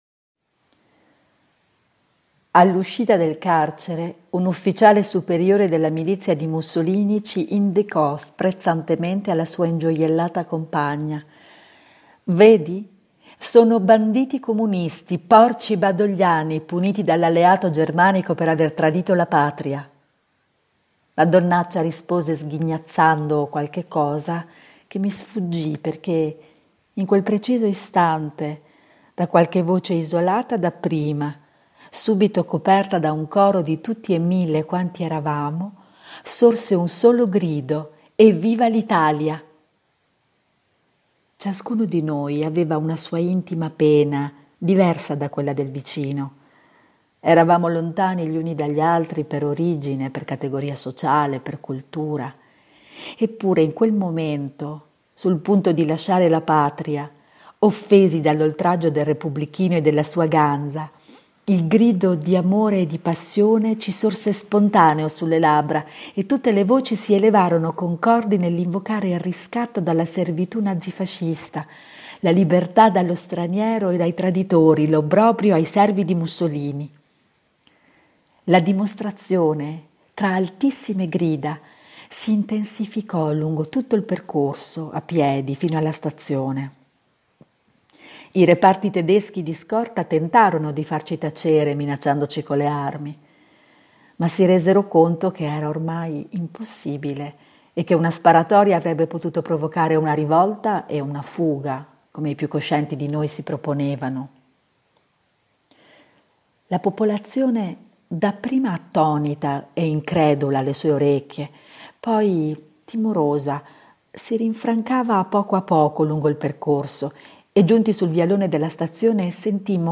2. Lettura